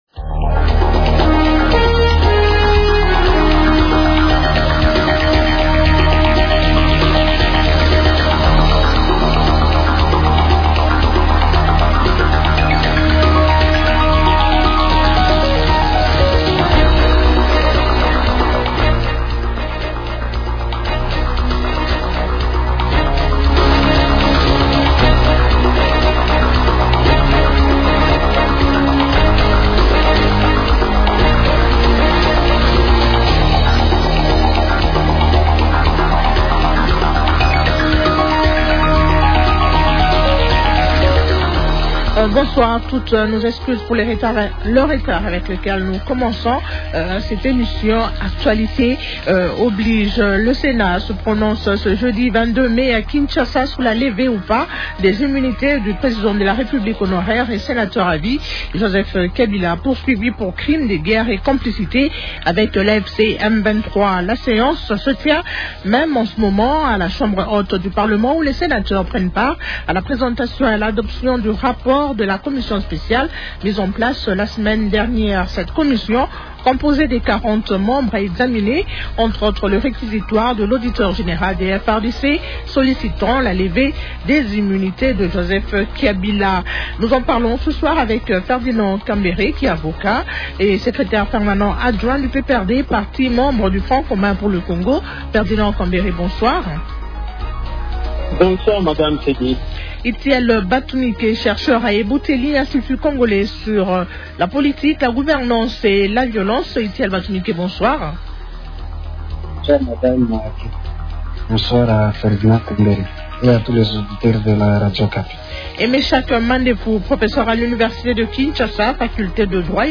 -Qu’est-ce qu’on peut attendre de cette démarche du Sénat ? Invités :